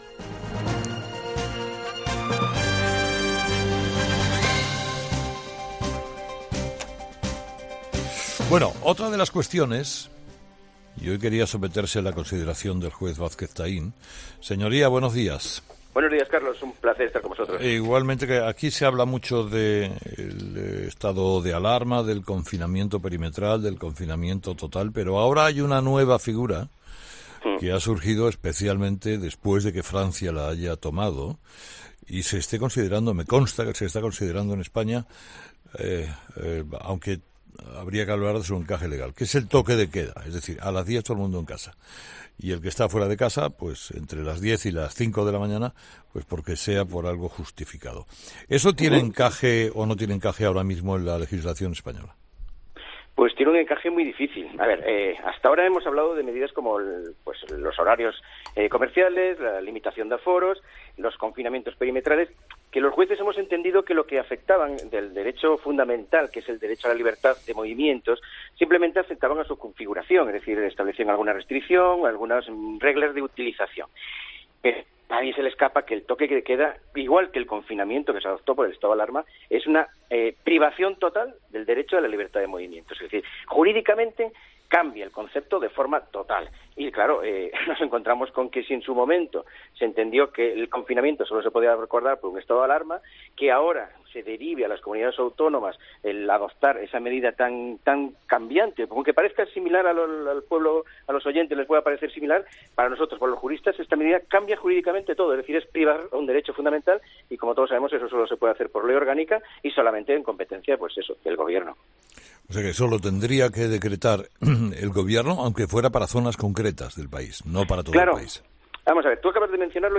El juez José Antonio Vázquez Taín ha analizado en ‘Herrera’ en COPE la polémica medida.